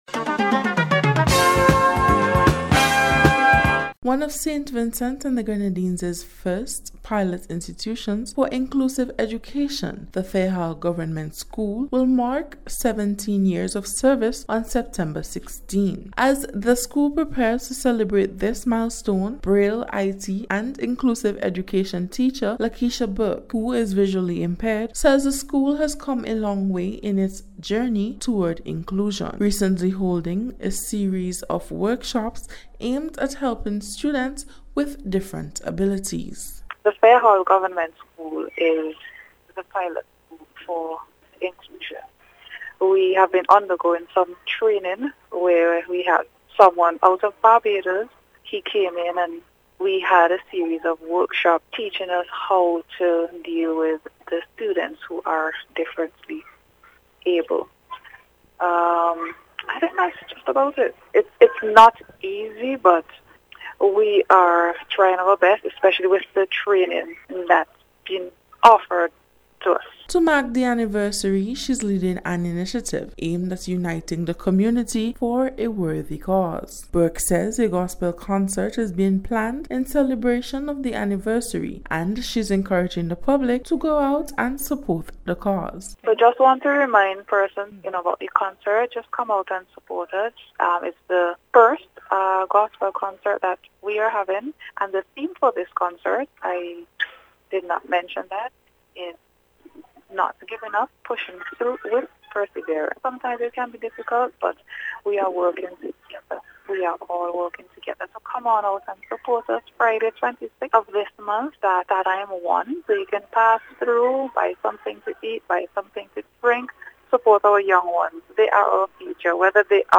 NBC’s Special Report- Friday 5th September,2025